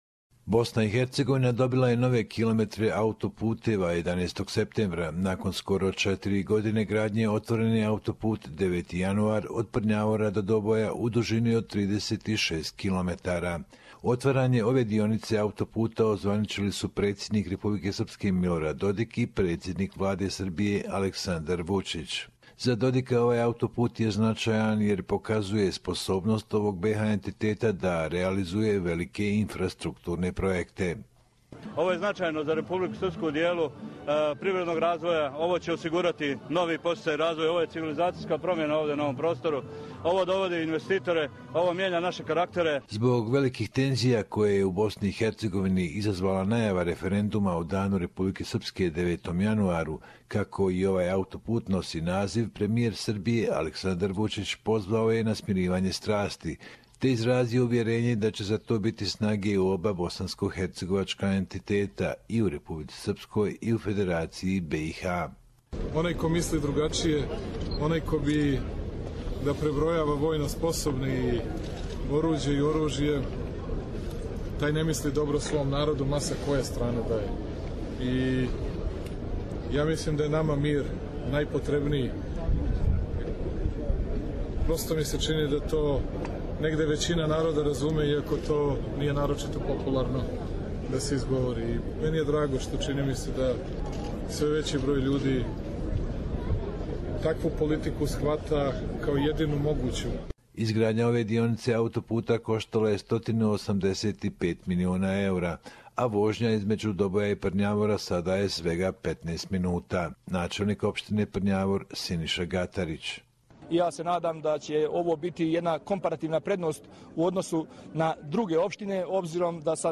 Dragan Covic: 18 years -we have built 100 kilometers of highways! Bosnia and Herzegovina report